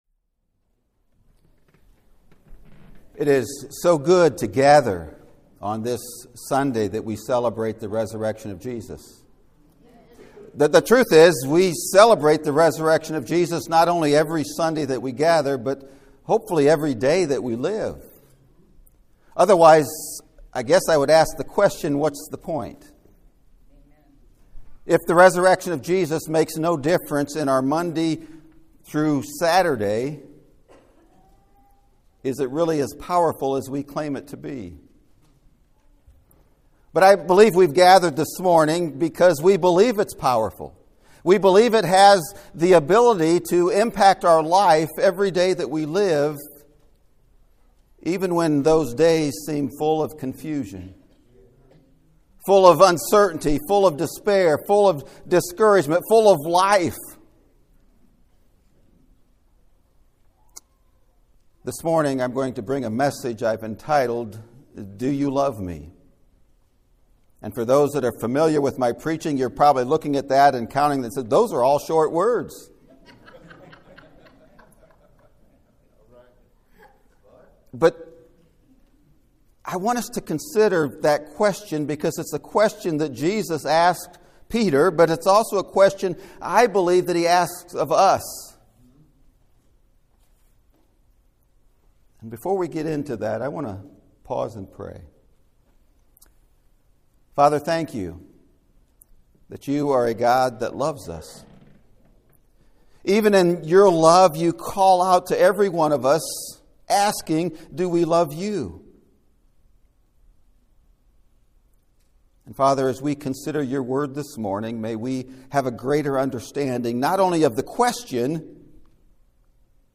Do You Love ME? (Sermon Audio)